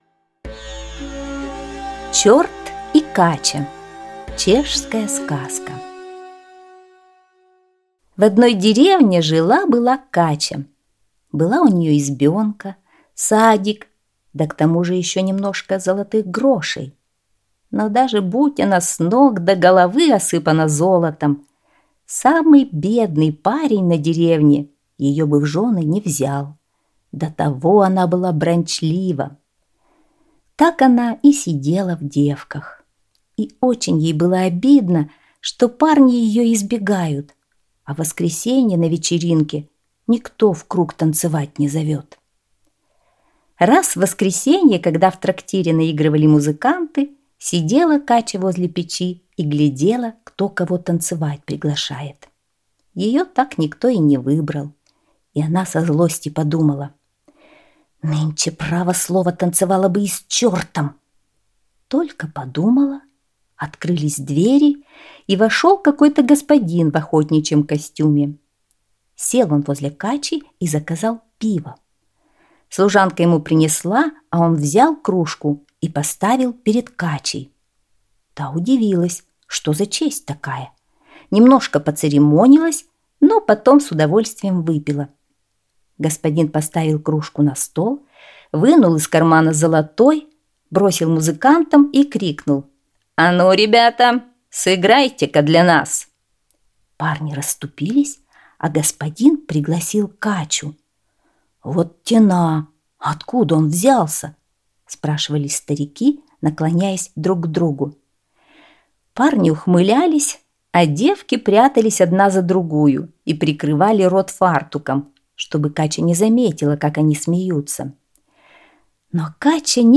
Чёрт и Кача - чешская аудиосказка - слушать онлайн